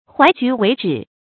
淮橘為枳 注音： ㄏㄨㄞˊ ㄐㄨˊ ㄨㄟˊ ㄓㄧˇ 讀音讀法： 意思解釋： 淮南的橘樹，移植到淮河以北就變為枳樹。